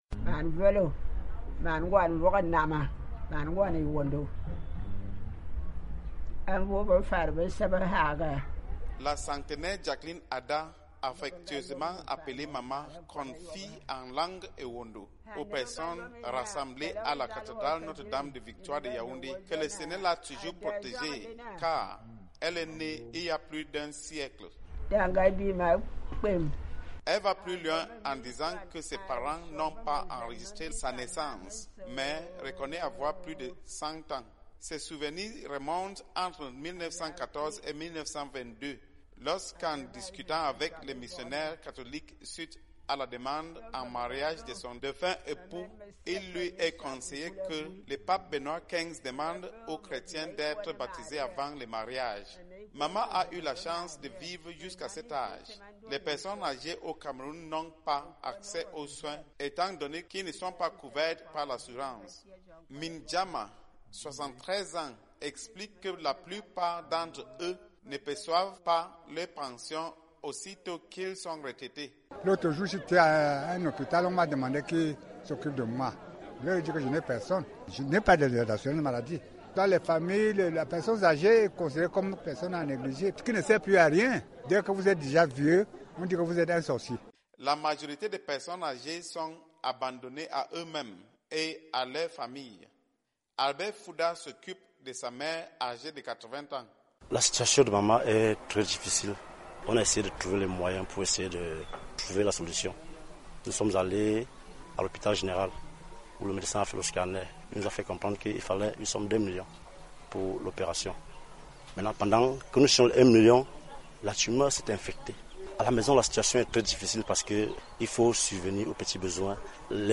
Les personnes âgées négligées au Cameroun- Reportage